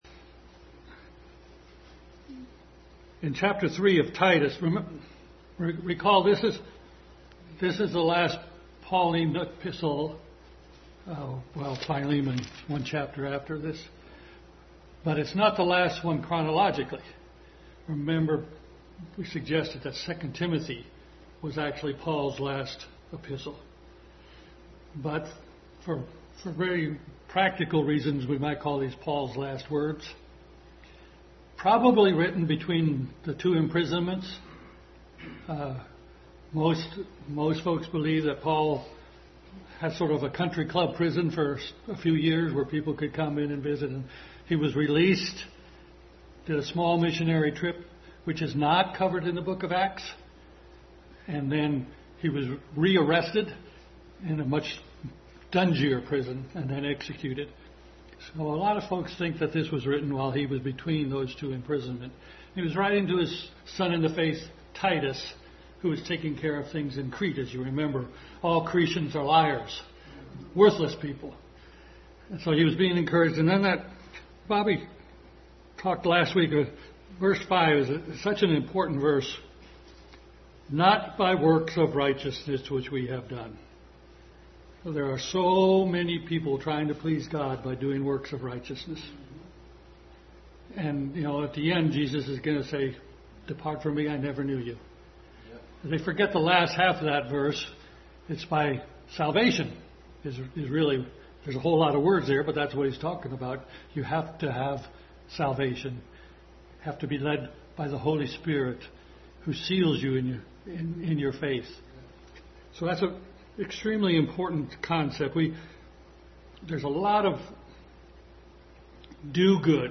Titus 3:9-15 and Daniel 1:1-21 Passage: Titus 3:9-15, Matthew 9:5, Daniel 1:1-21 Service Type: Sunday School